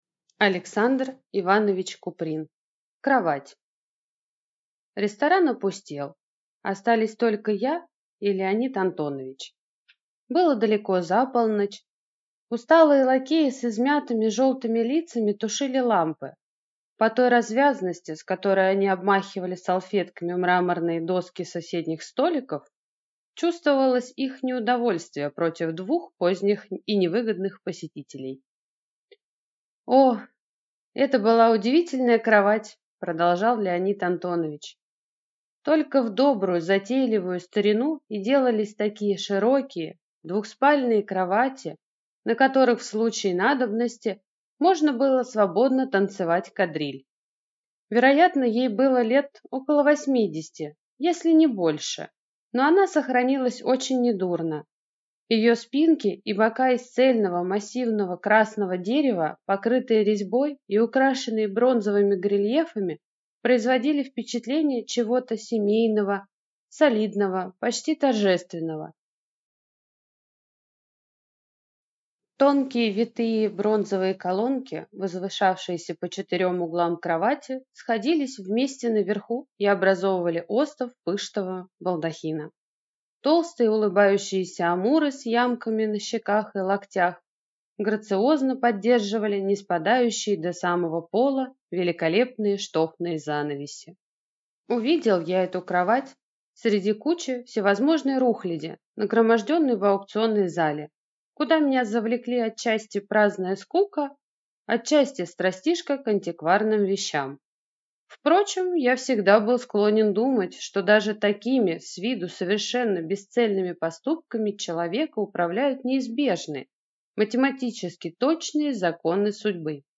Aудиокнига Кровать